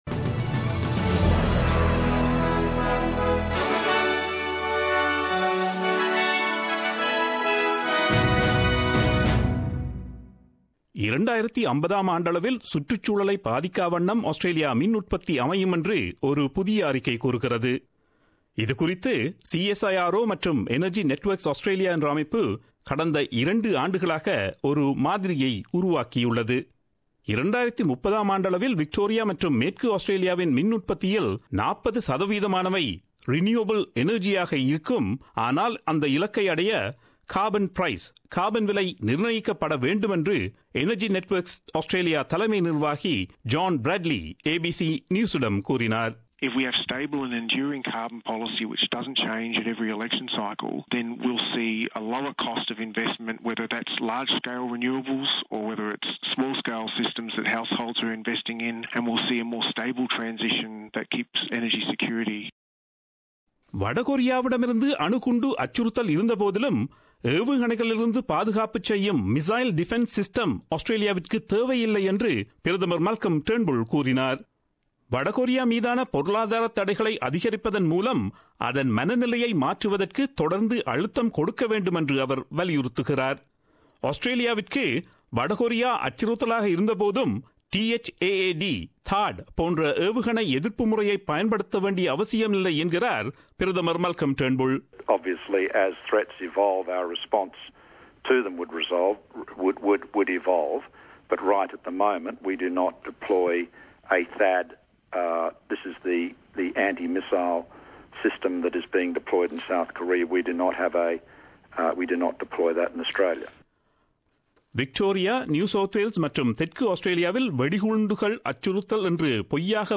Australian news bulletin aired on Friday 28 Apr 2017 at 8pm.